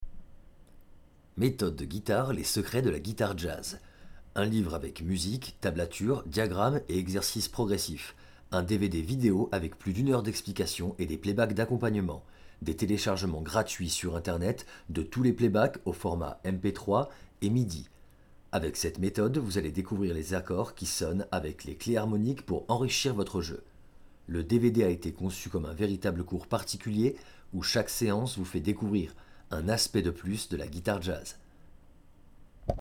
Sprechprobe: Sonstiges (Muttersprache):
I am a singer and comedian,i am very passionned by voice over, i can do imitations,cartoon also adapt my voice tone to the customers requests.